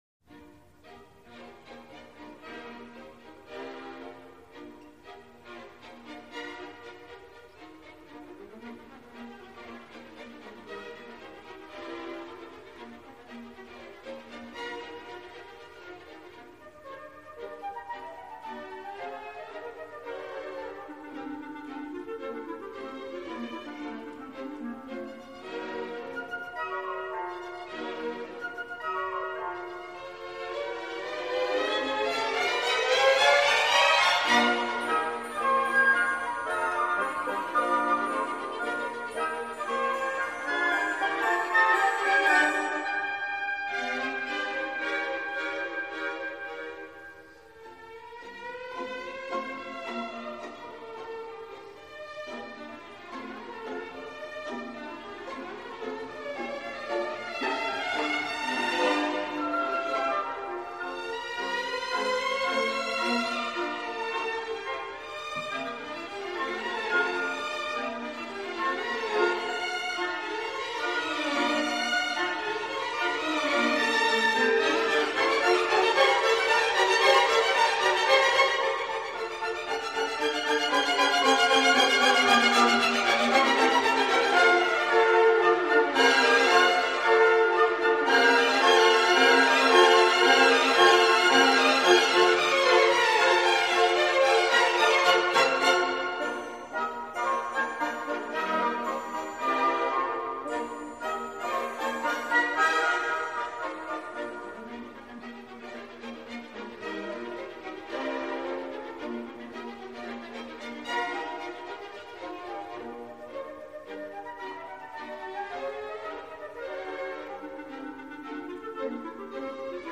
Классическая музыка